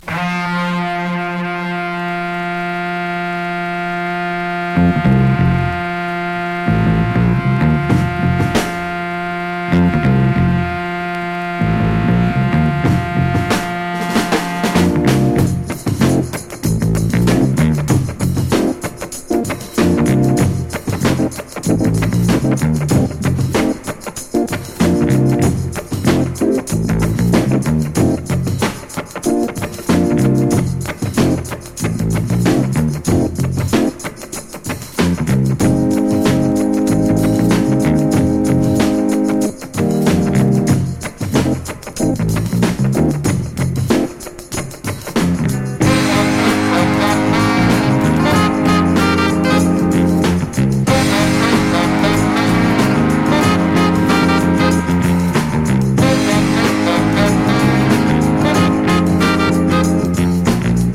テキサス産ディープファンク傑作7インチ。咆哮ホーンにグルーヴィー・ベースライン♥